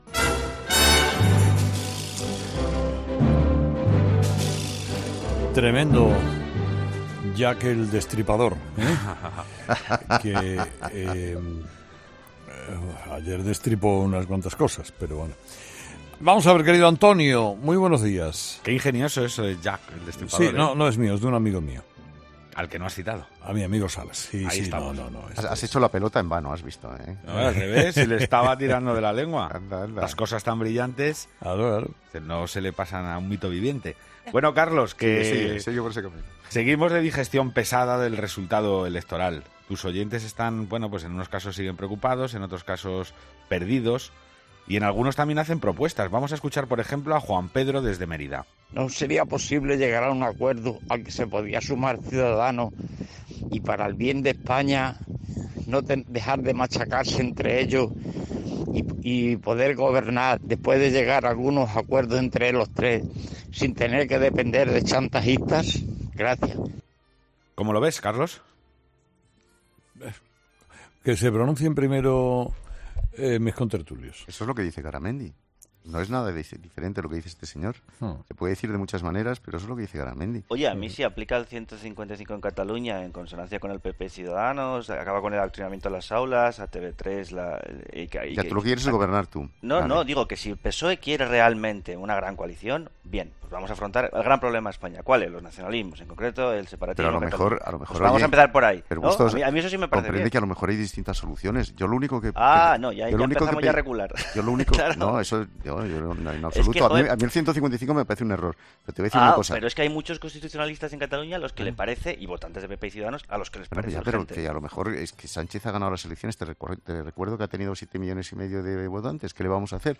Cada día, Carlos Herrera conversa con sus oyentes